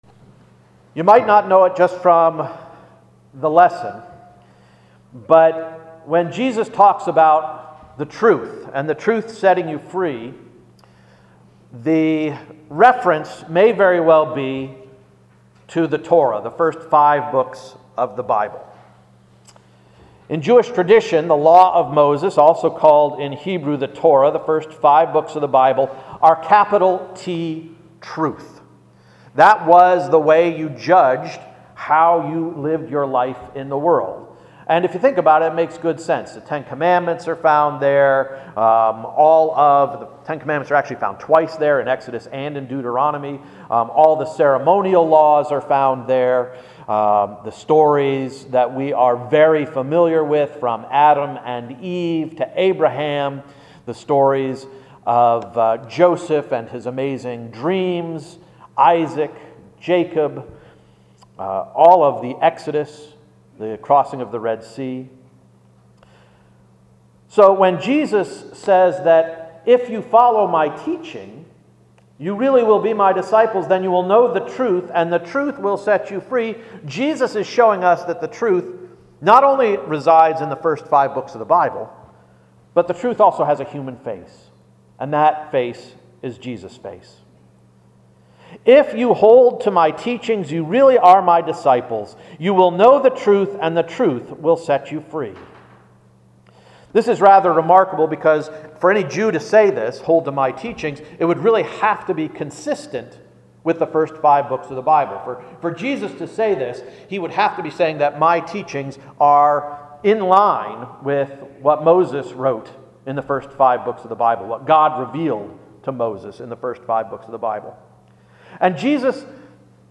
Sermon of January 14, 2018 — “Independence Day”